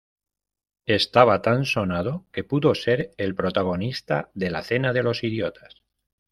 Read more Adj Verb 🔉 sonar Verb Noun Read more Frequency C2 Hyphenated as so‧na‧do Pronounced as (IPA) /soˈnado/ Etymology Past participle of sonar.